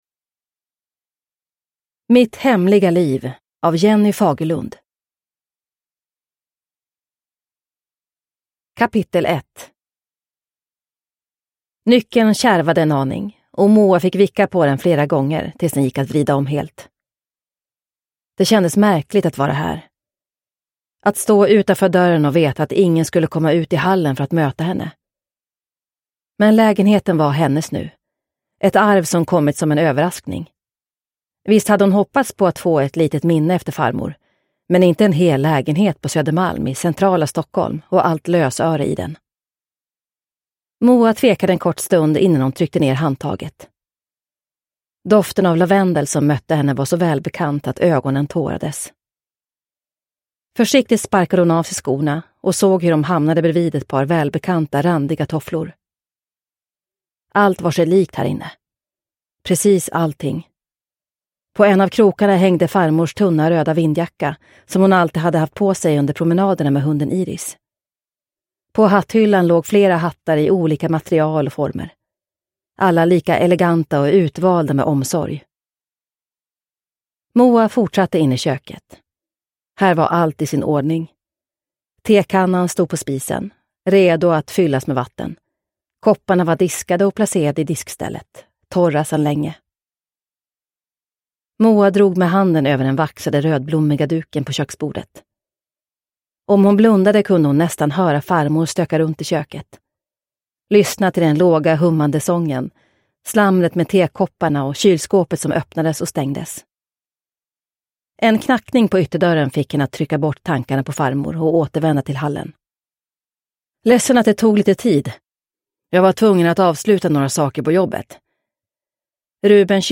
Mitt hemliga liv – Ljudbok – Laddas ner